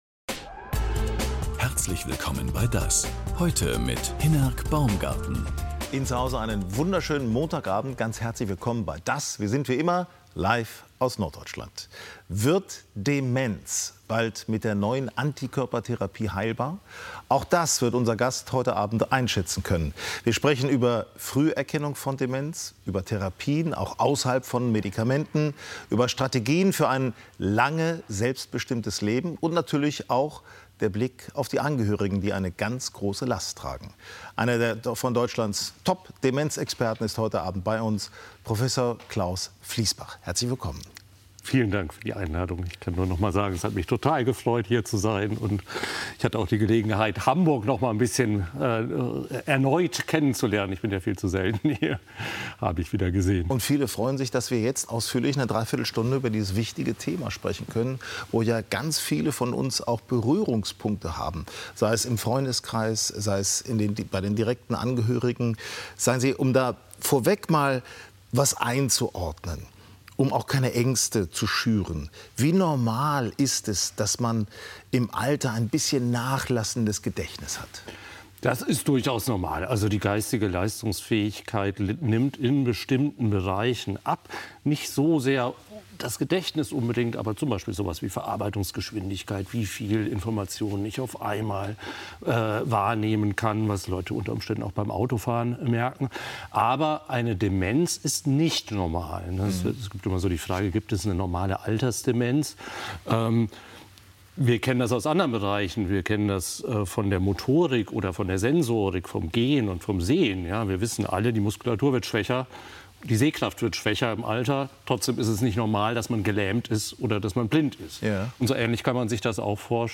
DAS! ist bekannt für intensive Interviews mit prominenten und kompetenten Gästen auf dem Roten Sofa. Die Gesprächsfassung (ohne Filmbeiträge) vom Vortag gibt es auch als Audio-Podcast.